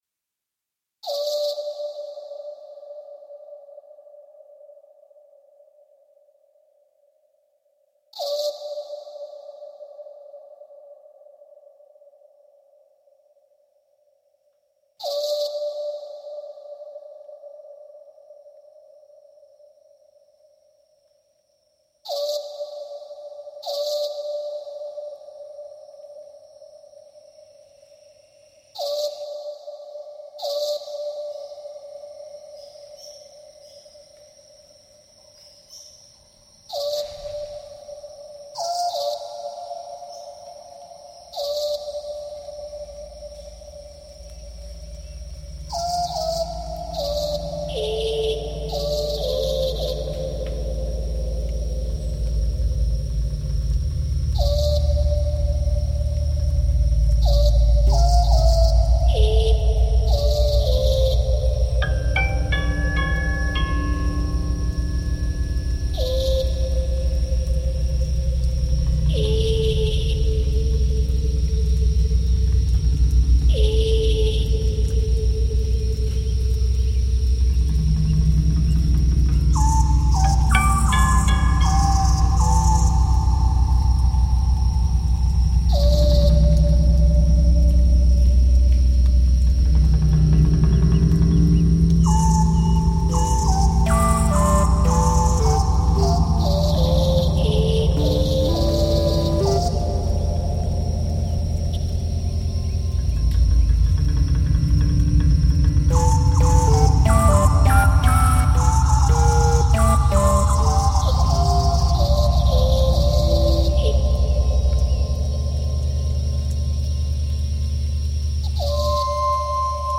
el.bass guitar